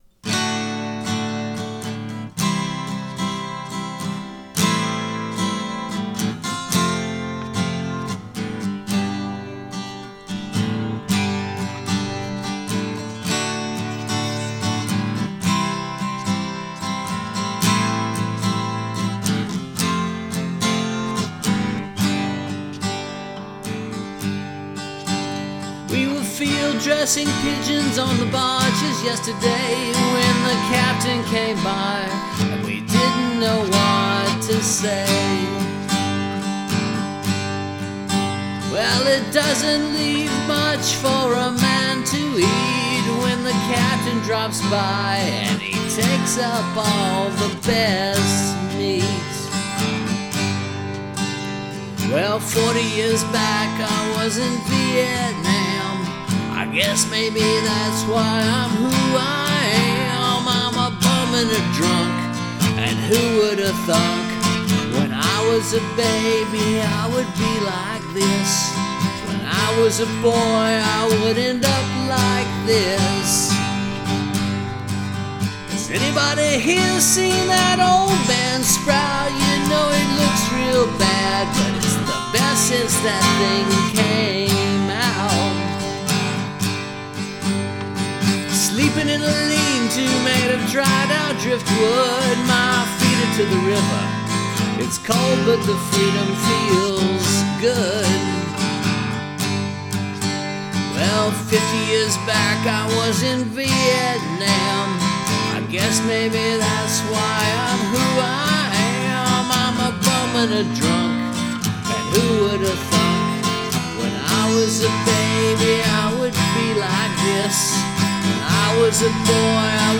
recorded live to two tracks Feb. 26 & 27 2014